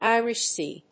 Írish Séa